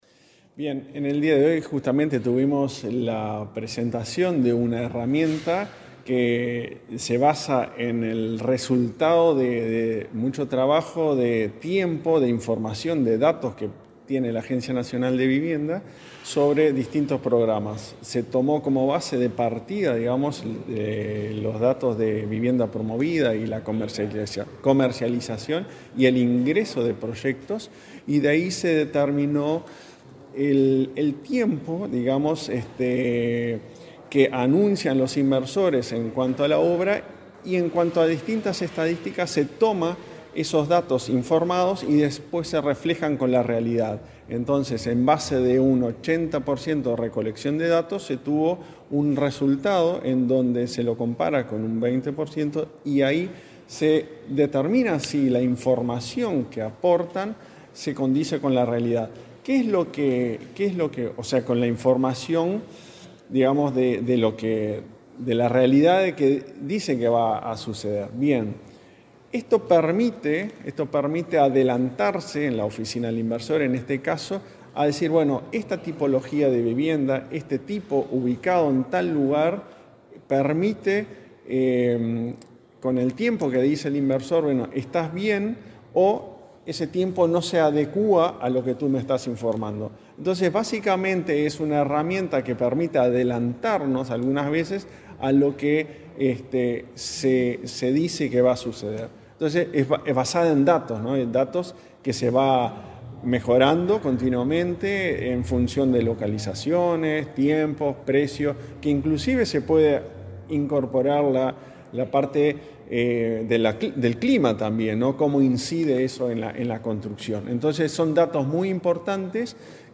Declaraciones del presidente de la ANV, Klaus Mill
Declaraciones del presidente de la ANV, Klaus Mill 16/12/2024 Compartir Facebook X Copiar enlace WhatsApp LinkedIn Este lunes 16, el presidente de la Agencia Nacional de Vivienda (ANV), Klaus Mill, participó en la presentación del análisis de datos de dos programas que lleva adelante ese organismo: Relocalizaciones y Vivienda Promovida. Luego, dialogó con Comunicación Presidencial.